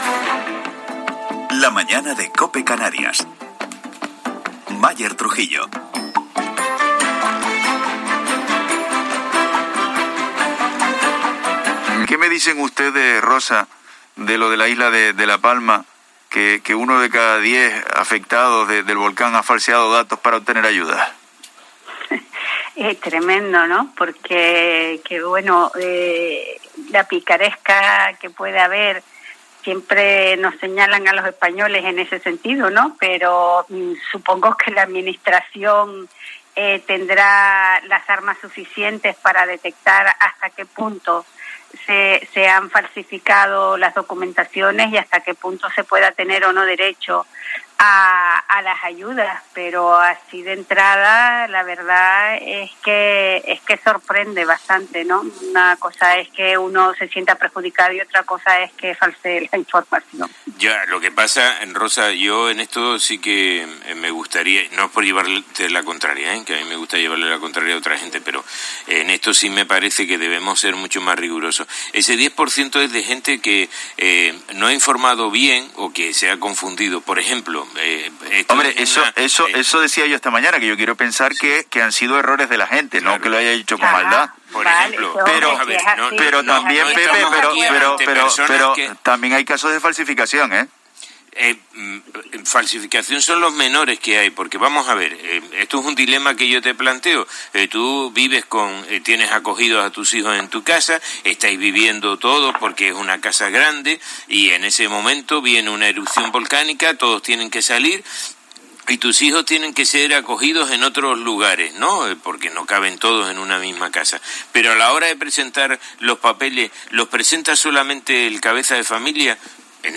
ha entrevistado